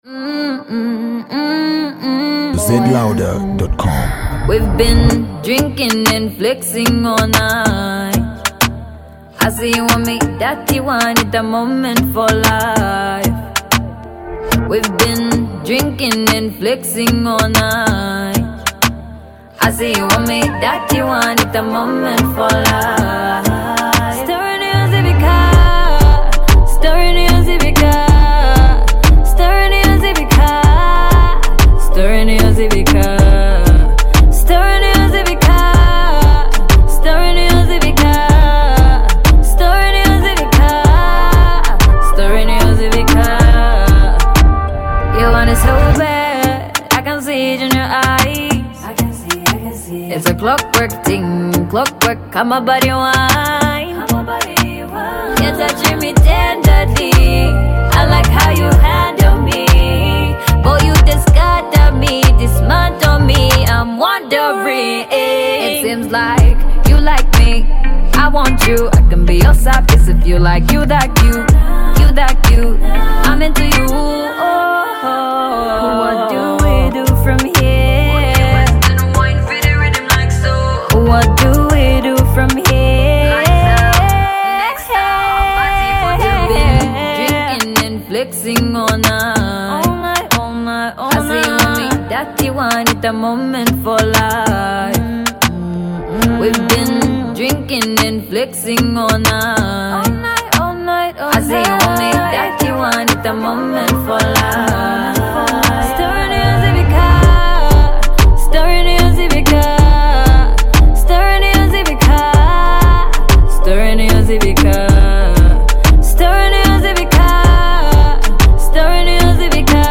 A zambian talented female vocalist